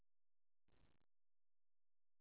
kyo 0141 (Monaural AU Sound Data)